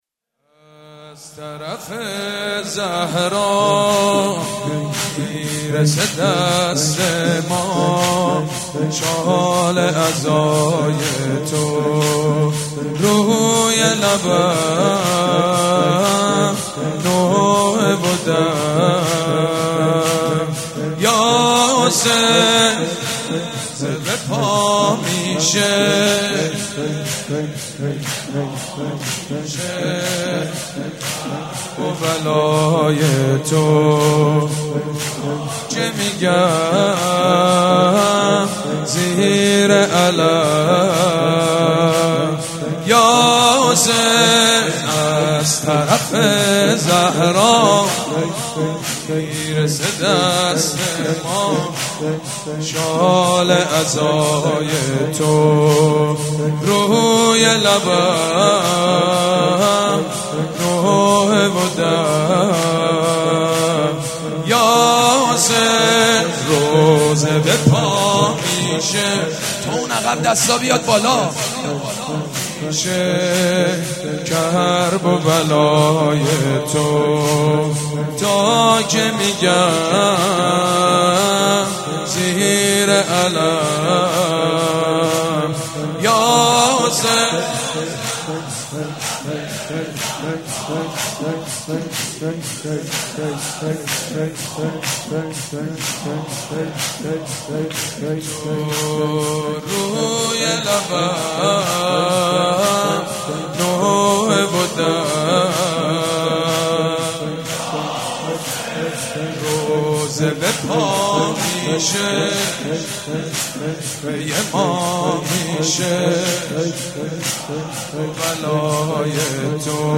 صوت مراسم شب سوم محرم ۱۴۳۷هیئت ریحانه الحسین(ع) ذیلاً می‌آید: